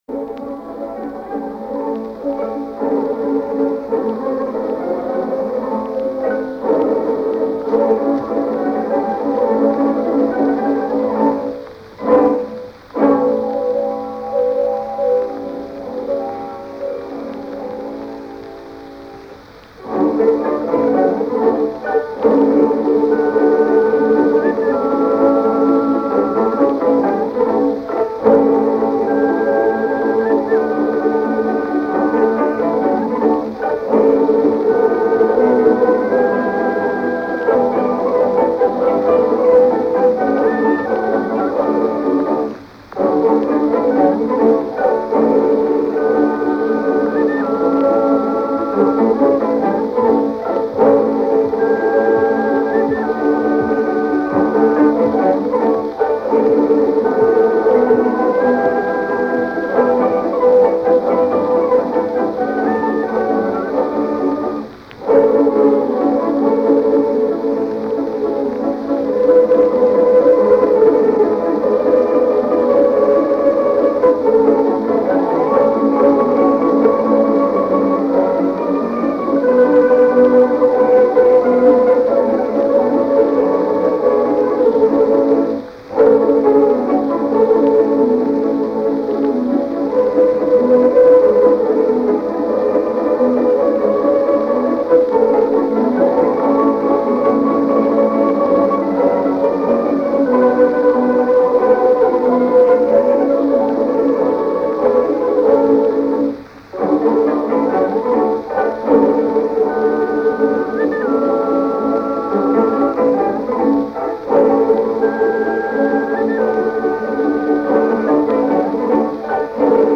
"Фавн" (исполн. Великорусский оркестр В. Андреева)